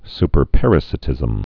(spər-părə-sĭ-tĭzəm, -sī-)